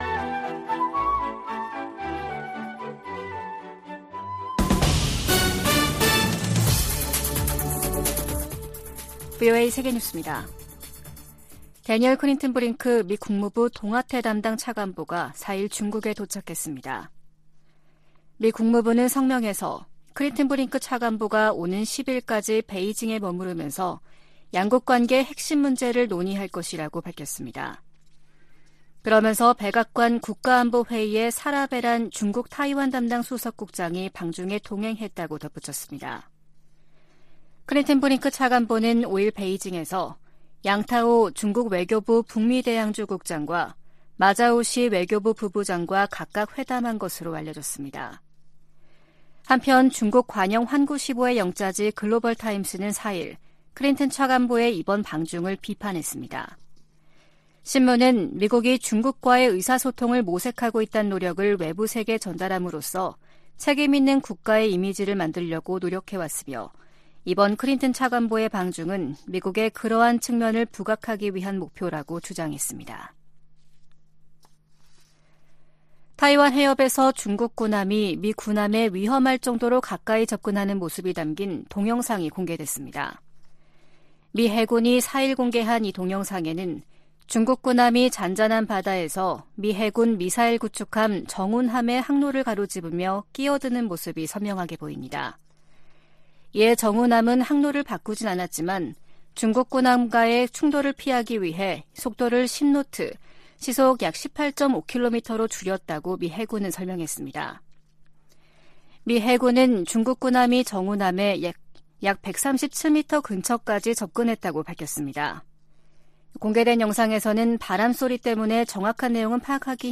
VOA 한국어 아침 뉴스 프로그램 '워싱턴 뉴스 광장' 2023년 6월 6일 방송입니다. 북한 서해위성발사장에서 로켓 장착용 조립 건물이 발사패드 중심부로 이동했습니다. 김여정 북한 노동당 부부장은 유엔 안보리가 군사정찰위성 발사를 단독 안건으로 논의한 데 대해 비난했습니다. 안보리가 북한의 위성 발사에 관한 긴급 공개회의를 개최했지만 공식 대응에는 합의하지 못했습니다.